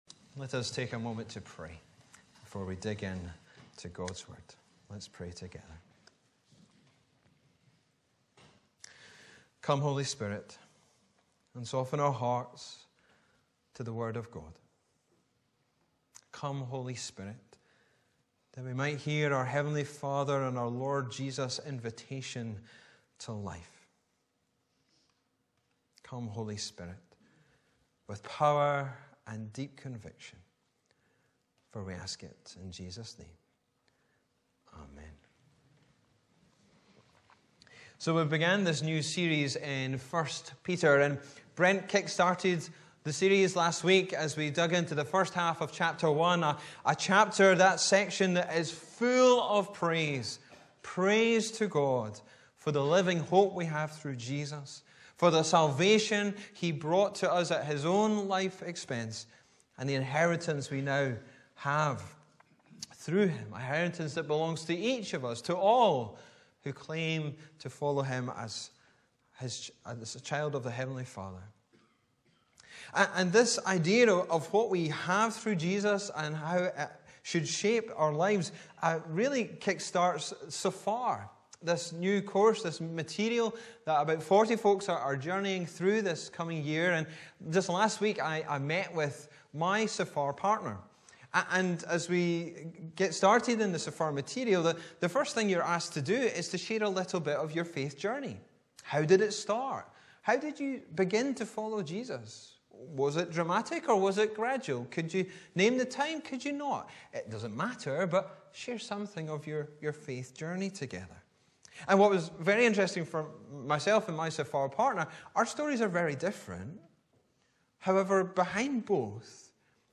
Jan 14, 2024 Hope MP3 Subscribe to podcast Notes Sermons in this Series Preached on: Sunday 14th January 2024 The sermon text is available as subtitles in the Youtube video (the accuracy of which is not guaranteed).
Bible references: 1 Peter 1:13-25 Location: Brightons Parish Church